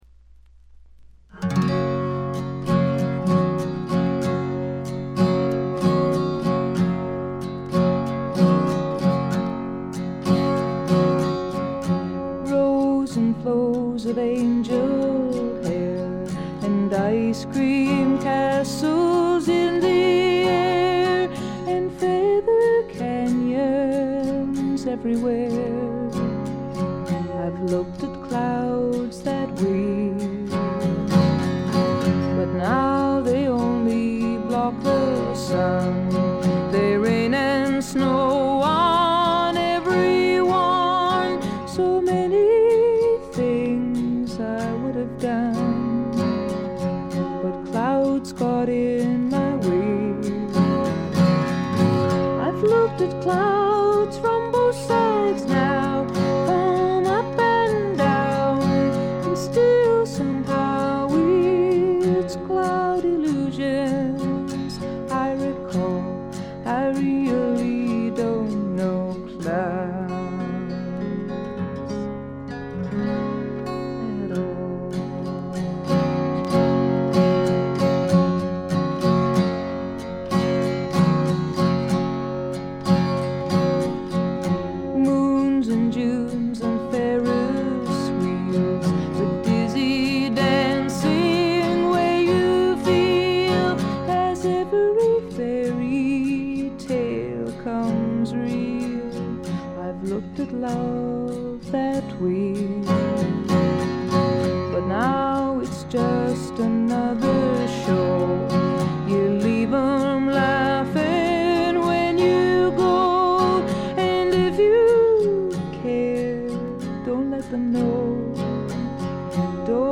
これ以外は微細なバックグラウンドノイズ程度。
透明感のあるみずみずしさが初期の最大の魅力です。
女性フォーク／シンガーソングライター・ファンなら避けては通れない基本盤でもあります。
試聴曲は現品からの取り込み音源です。